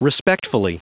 Prononciation du mot respectfully en anglais (fichier audio)
Prononciation du mot : respectfully